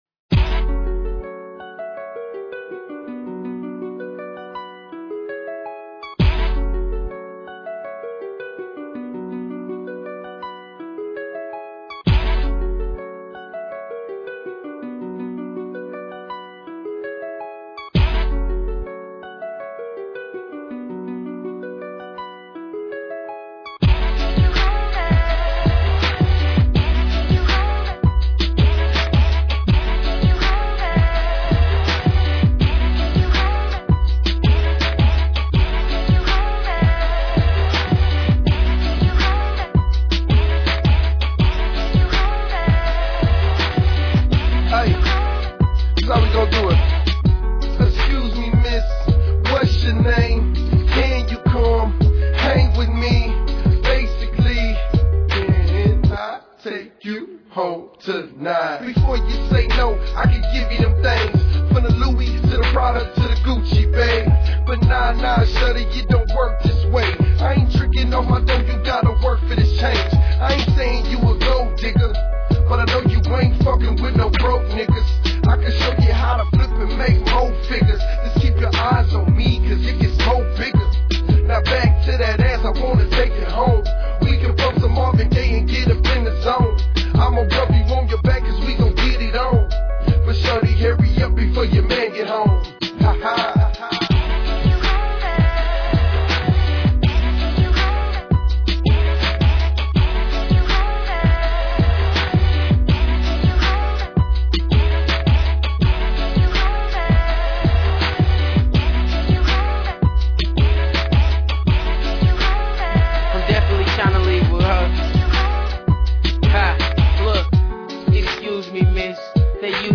Tags: rap, mp3